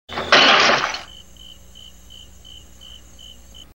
• POT BREAKING.wav
[POT-BREAKING]_Tp0.wav